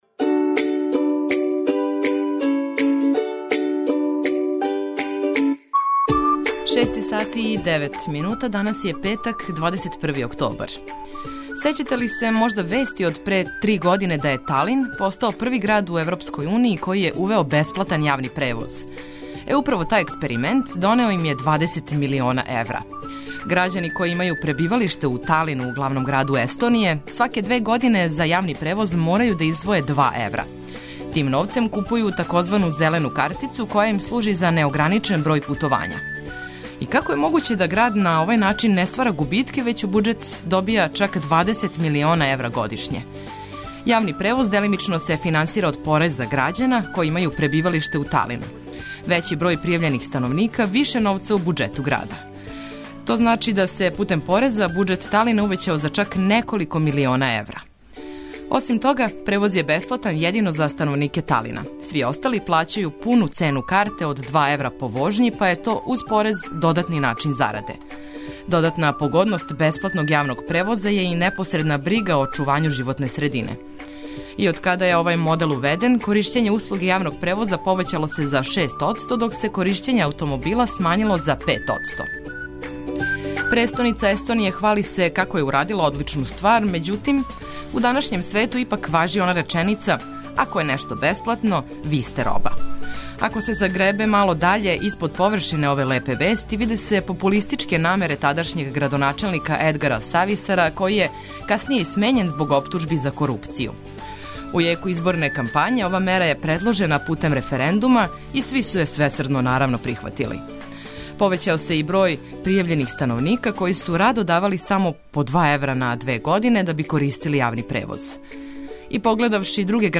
Током целог јутра ту су и спортске, сервисне информације, вести у сваком сату, и наравно, много добре музике за добро јутро!